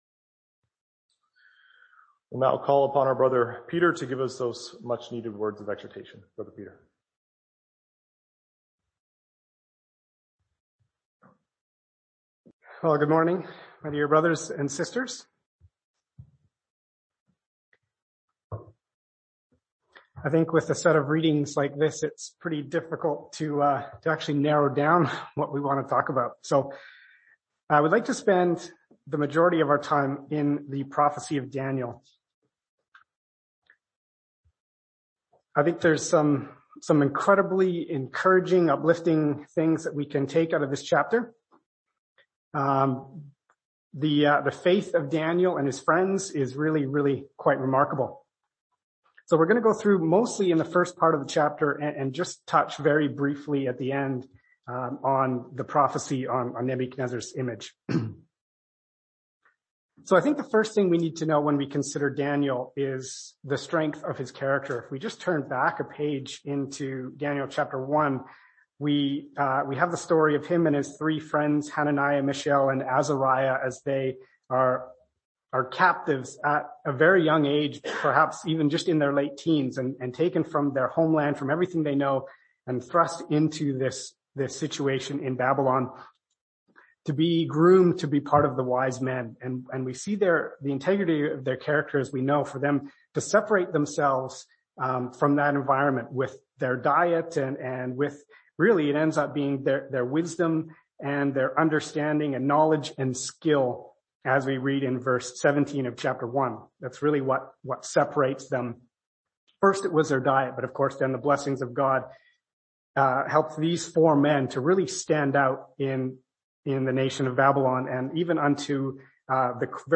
Exhortation 10-24-21